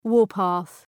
Shkrimi fonetik {‘wɔ:rpɑ:ɵ}